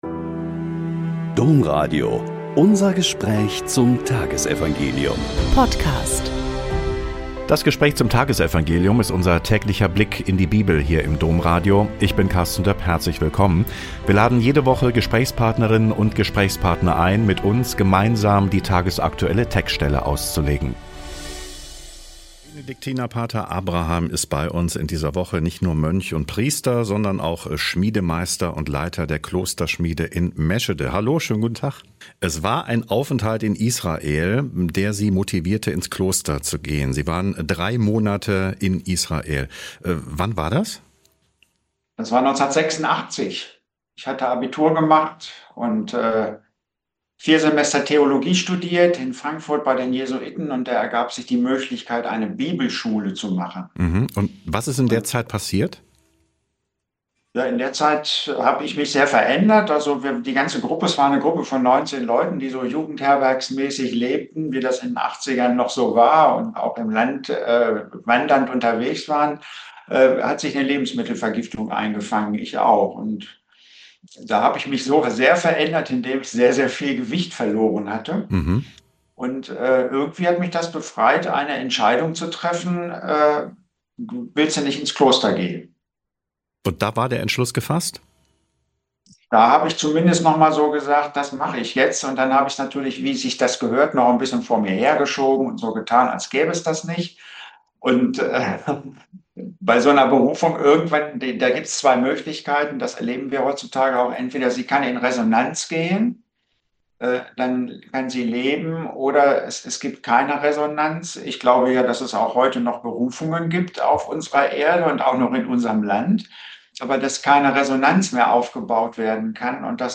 Lk 21,12-19 - Gespräch